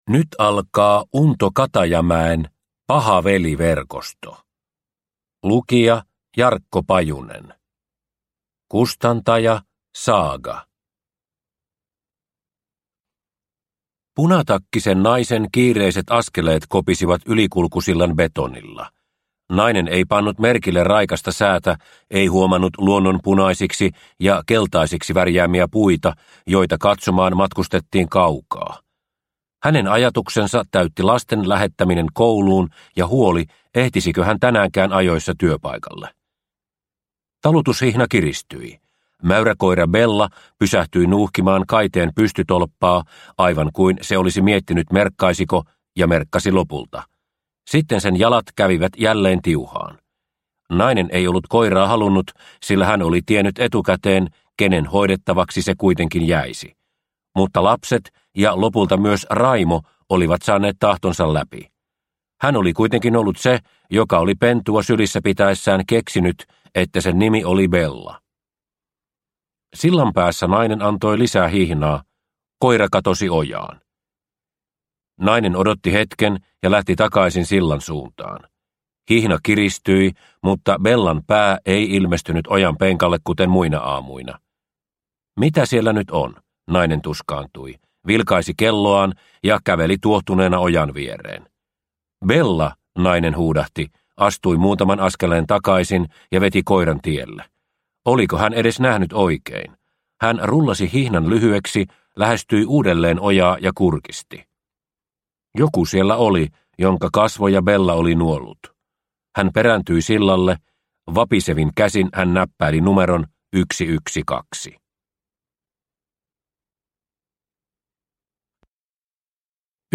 Paha veli -verkosto / Ljudbok